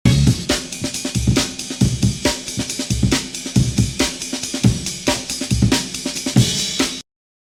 six-second drum solo